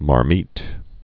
(mär-mēt)